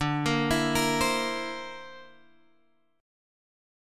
D 7th Suspended 2nd Sharp 5th